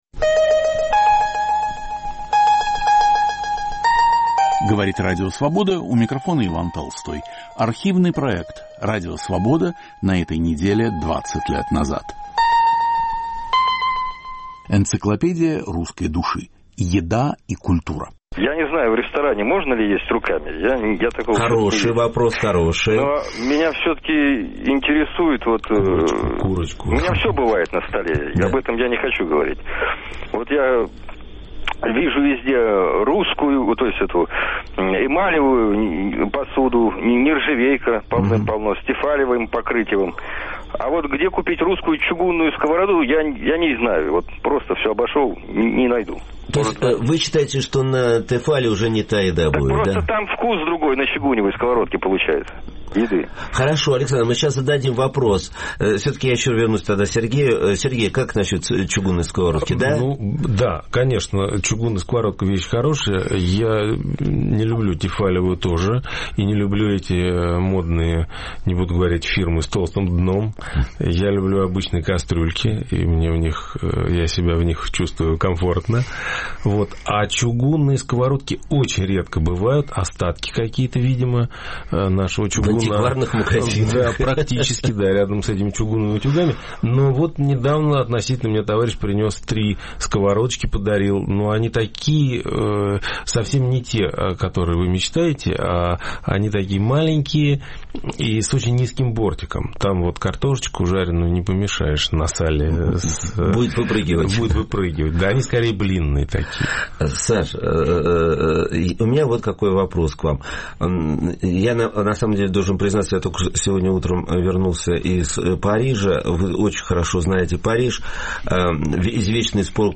Передачу подготовил и ведет Виктор Ерофеев.